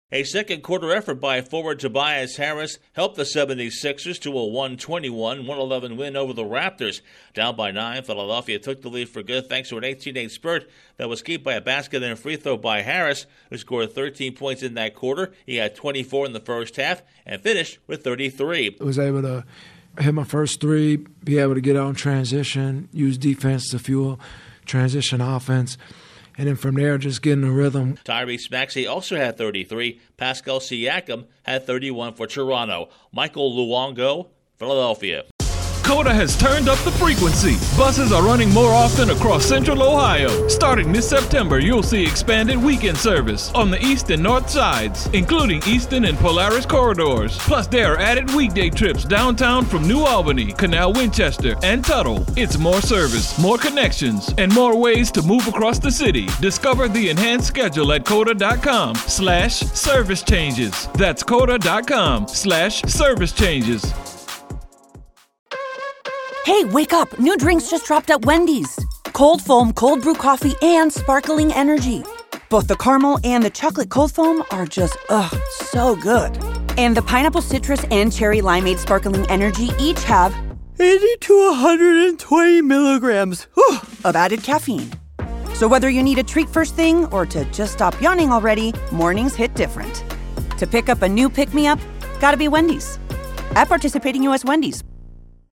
The 76ers struggle early before defeating the Raptors. Correspondent